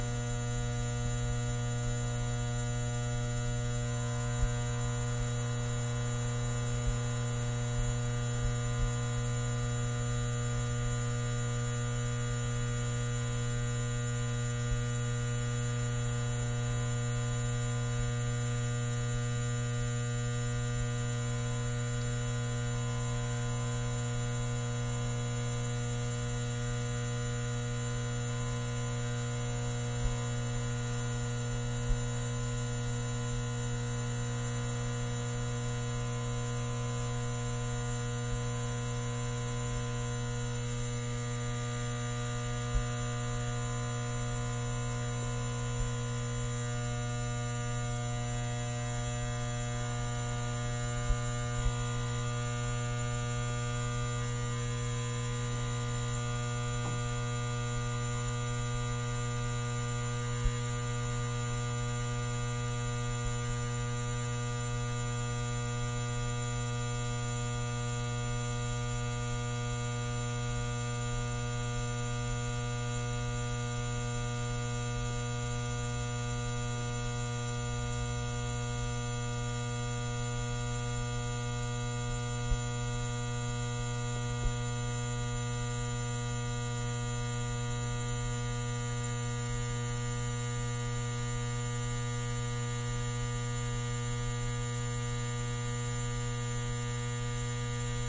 随机 " 霓虹灯嗡嗡嗡立体声接近低切的味道7
描述：霓虹灯嗡嗡声嗡嗡声立体声关闭lowcut to taste7.flac